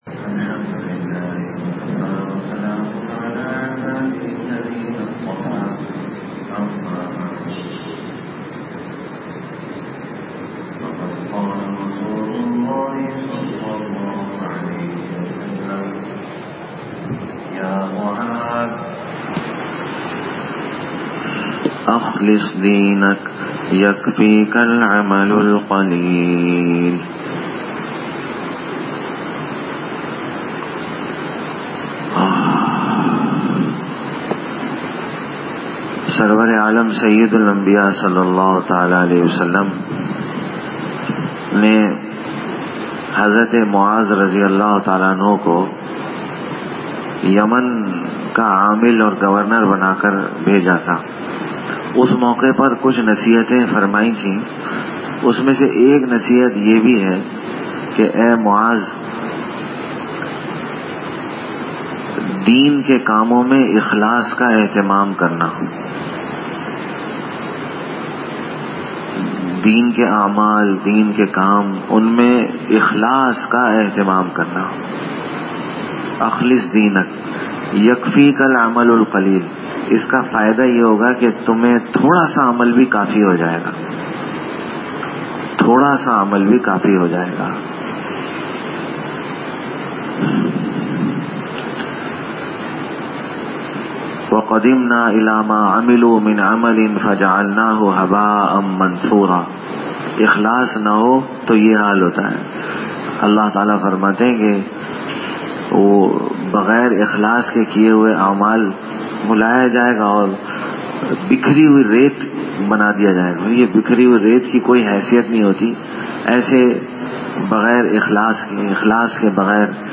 Dars e Hadees 12th Ramzan 1439Hijri